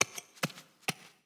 SFX_Hacke_03_Reverb.wav